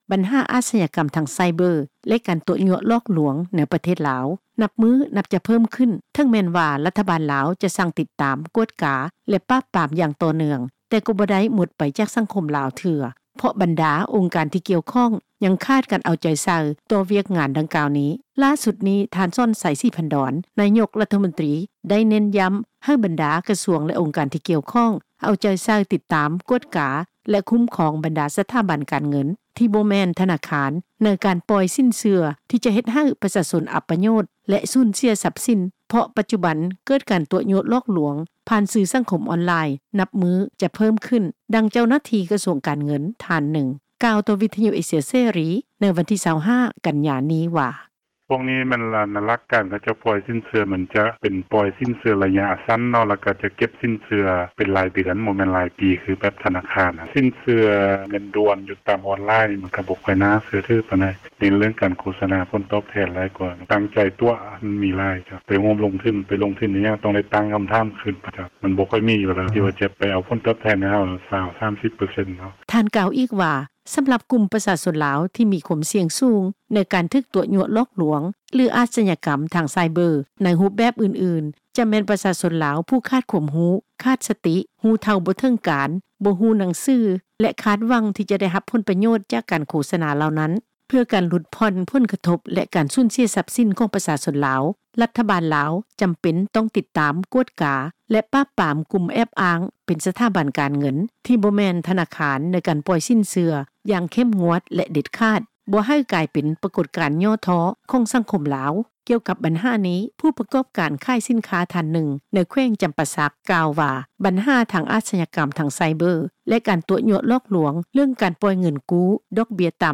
ດັ່ງ ເຈົ້າໜ້າທີ່ ກະຊວງການເງິນ ທ່ານນຶ່ງ ກ່າວຕໍ່ວິທຍຸ ເອເຊັຽເສຣີ ໃນມື້ວັນທີ 25 ກັນຍາ ນີ້ວ່າ:
ດັ່ງ ຜູ້ປະກອບການທ່ານນີ້ ກ່າວຕໍ່ວິທຍຸ ເອເຊັຍເສຣີ ໃນມື້ດຽວກັນນີ້ວ່າ: